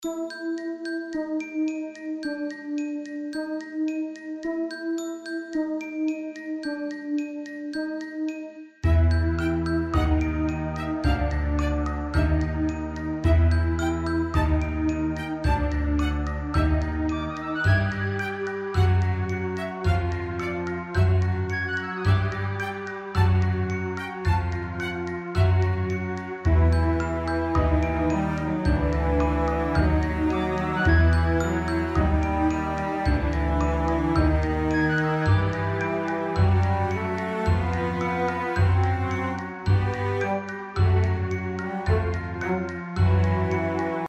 不安・迷子・ファンタジー系のアンビエントBGMです。
森の空気が肌に触れるような冷たさ、かすかな足音、自分の鼓動だけが響くような静寂——。
• Em / E♭解決しない進行で不安を演出
• add9・sus4 を混ぜて透明感を強調
• 柔らかいパッド中心のアンビエント構成
• 風・揺れをイメージした淡いノイズレイヤー
• 主張しない、細い線のような旋律
• ローエンドを控えめにし、軽さと不安感の両立